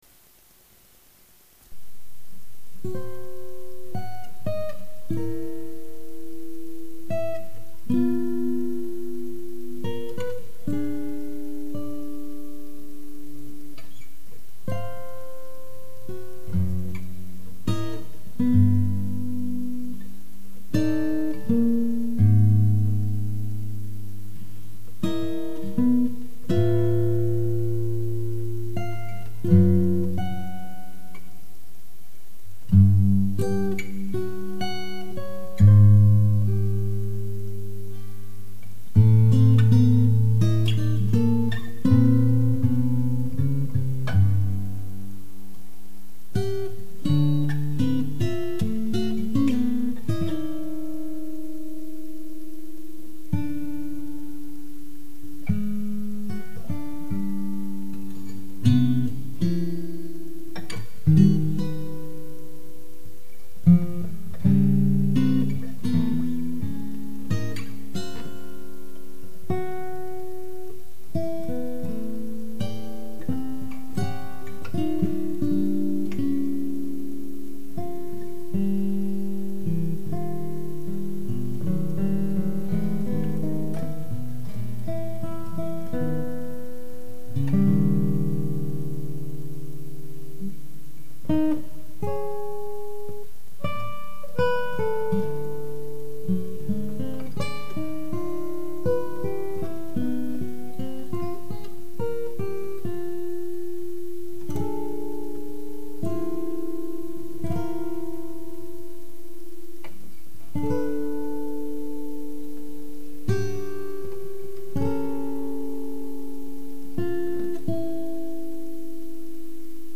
Duet for Two Guitars